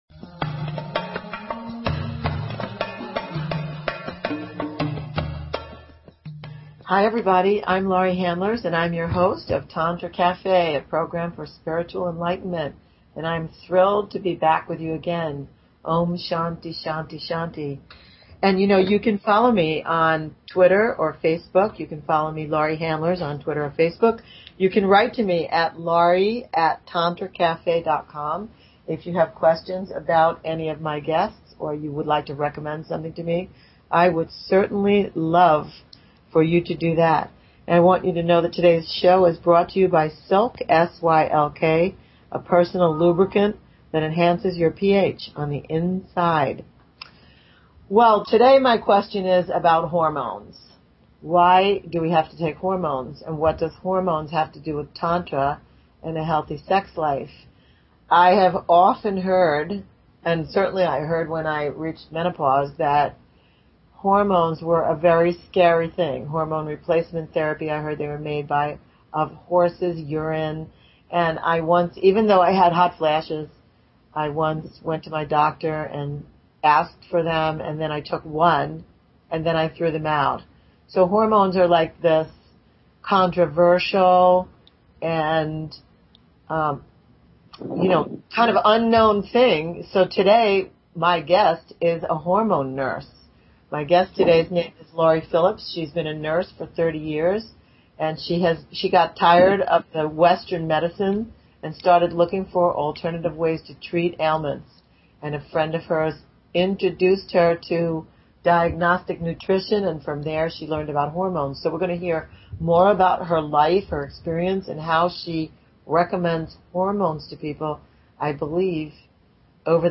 Talk Show Episode, Audio Podcast, Tantra_Cafe and Courtesy of BBS Radio on , show guests , about , categorized as